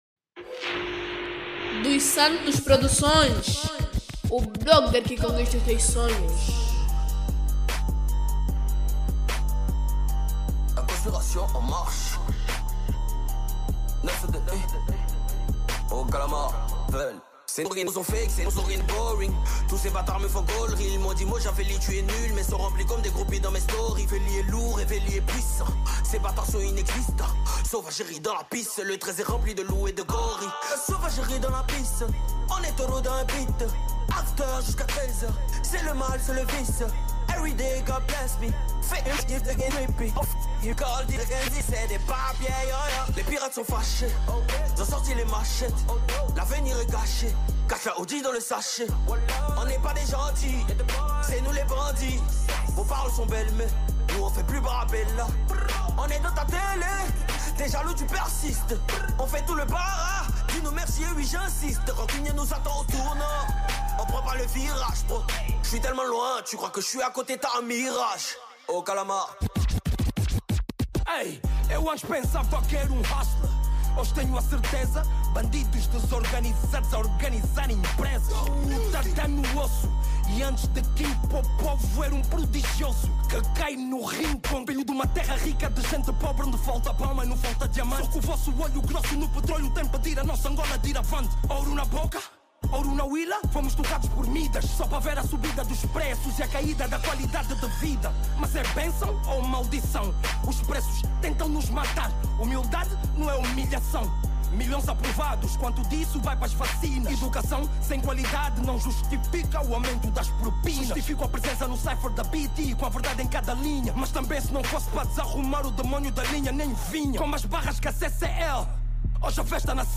Categoria   Cypher